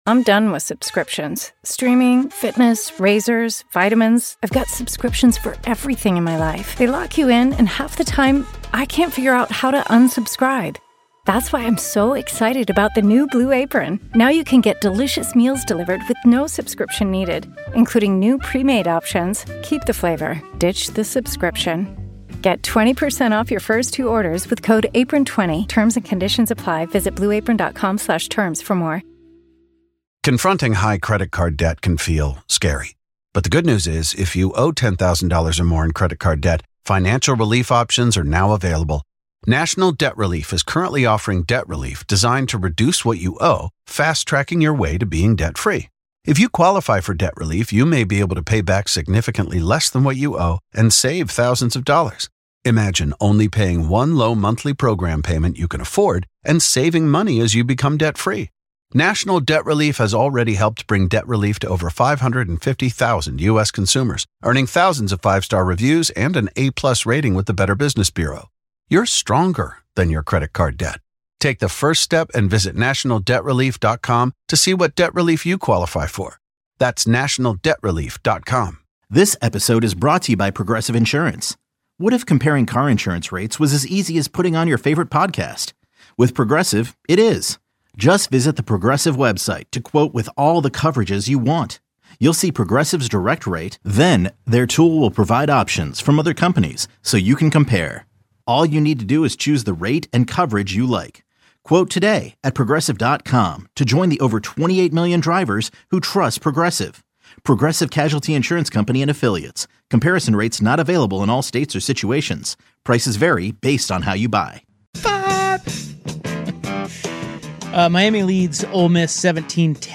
Portland's iconic sports talk show.